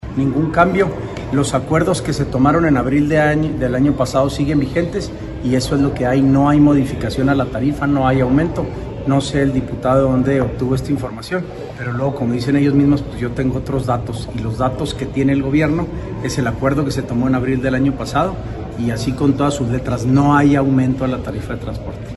AUDIO: SANTIAGO DE LA PEÑA GRAJEDA, TITULAR DE LA SECRETARÍA GENERA DE GOBIERNO